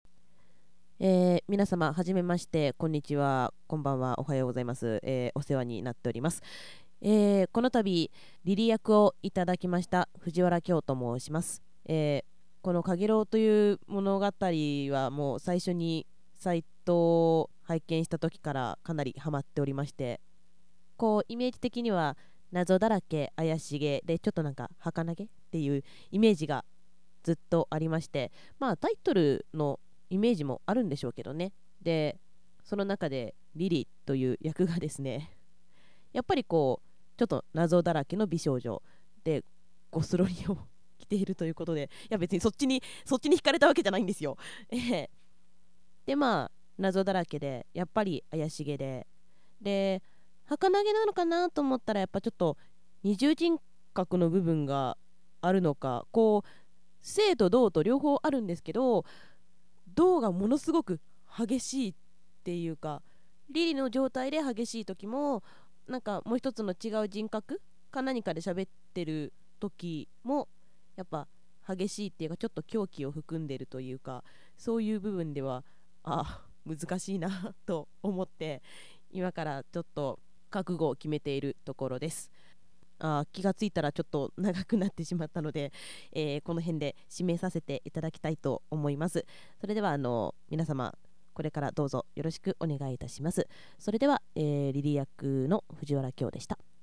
Comment voice